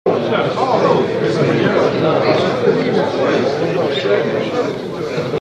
PEOPLE MURMURING.mp3
Original creative-commons licensed sounds for DJ's and music producers, recorded with high quality studio microphones.
people_murmuring_uu2.ogg